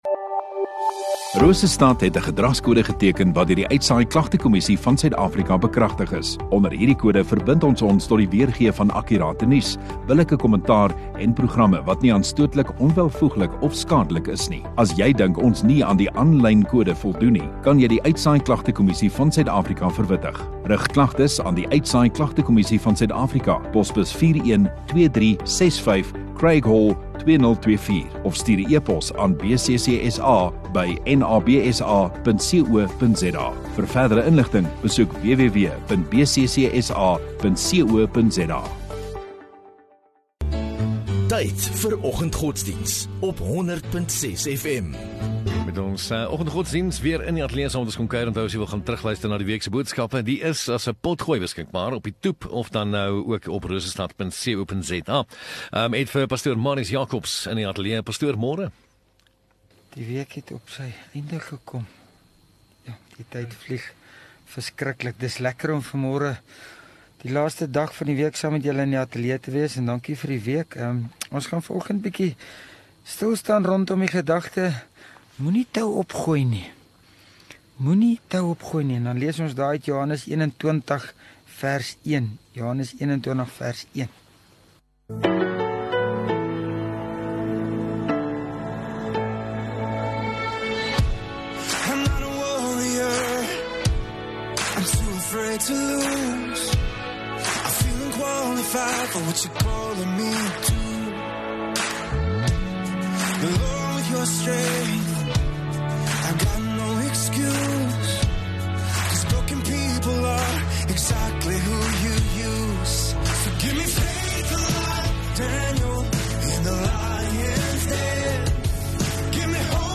17 May Vrydag Oggenddiens